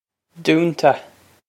Dúnta Doon-ta
This is an approximate phonetic pronunciation of the phrase.